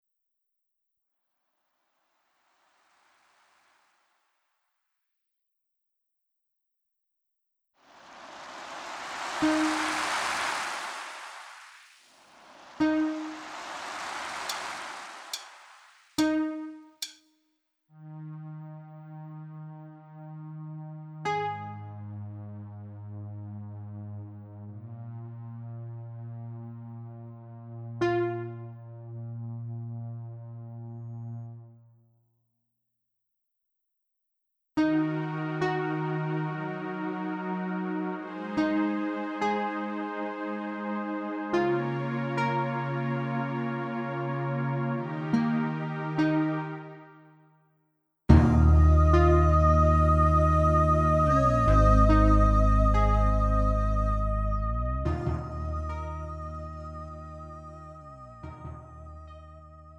Lite MR은 저렴한 가격에 간단한 연습이나 취미용으로 활용할 수 있는 가벼운 반주입니다.
장르 가요